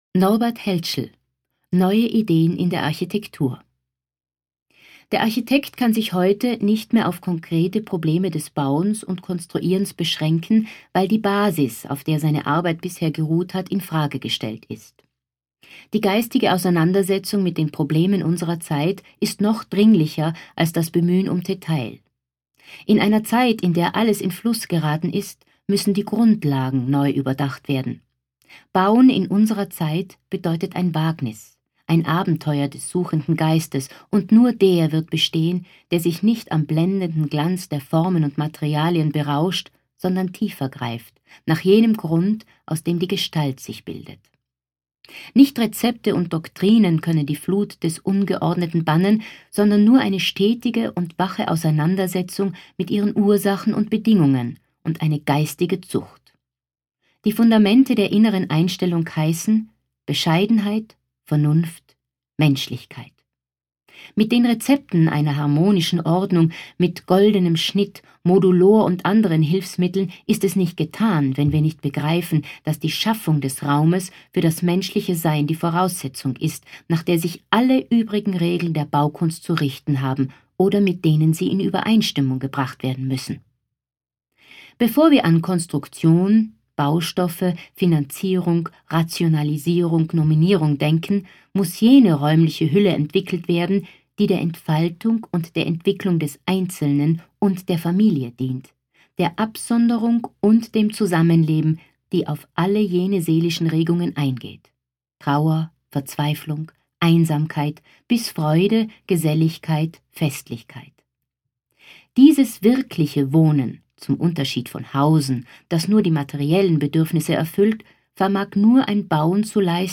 Das anlässlich der Eröffnung von aut im Adambräu erscheinende Lesebuch „reprint“ war die Basis für 11 Hörstationen in der 2005 gezeigten Eröffnungsausstellung vermessungen.